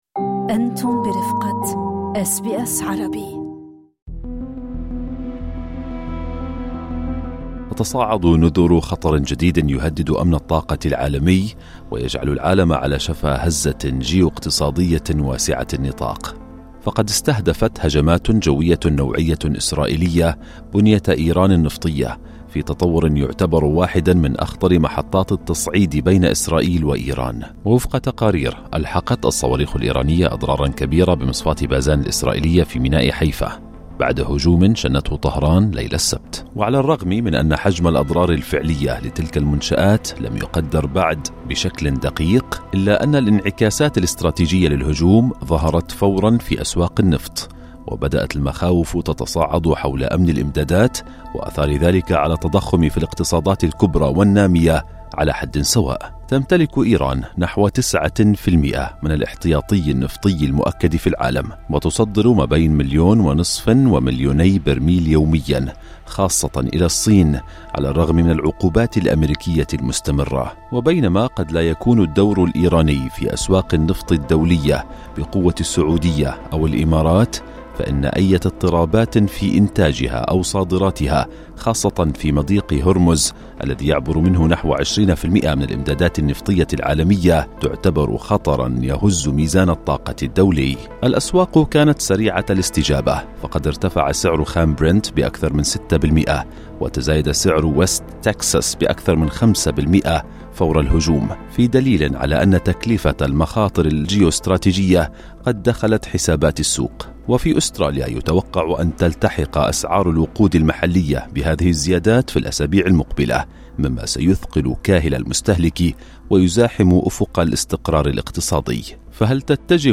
سألنا خبير النفط العالمي